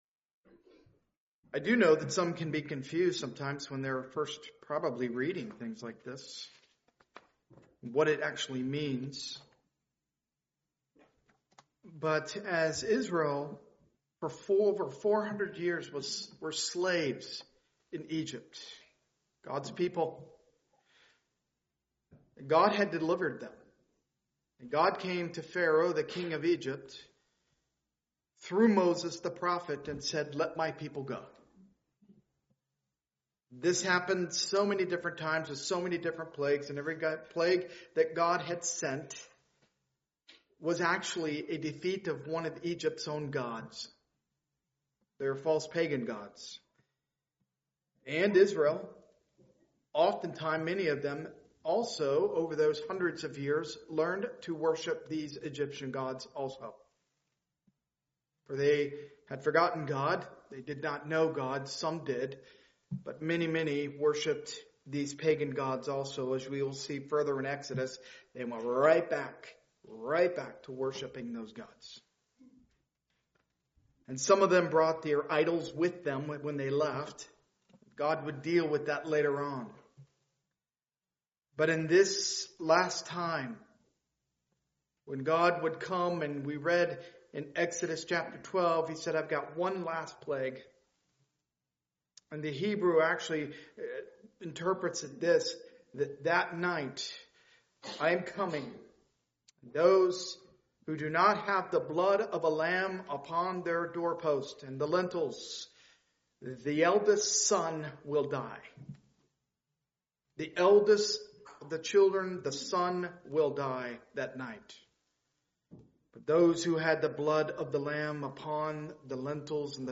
Part of the The Book of Exodus series, preached at a Morning Service service.